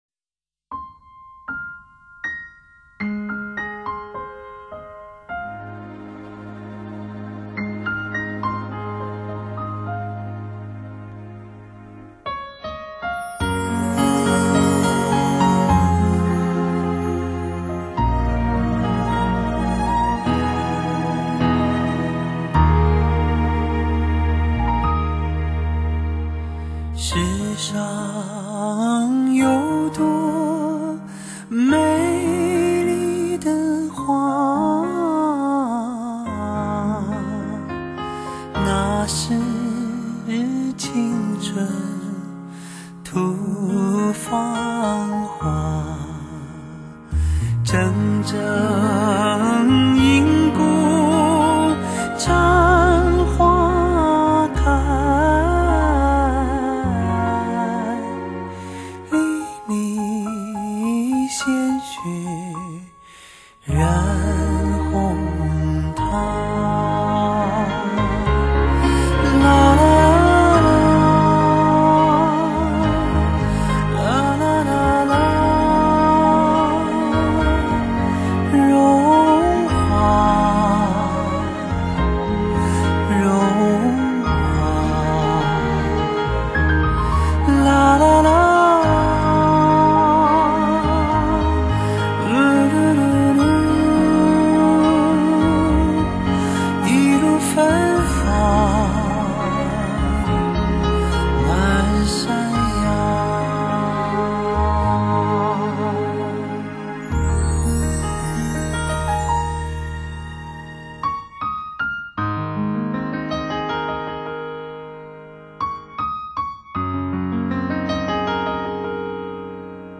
寂寞星空，寥寥星辰，磁性、清澈的声音，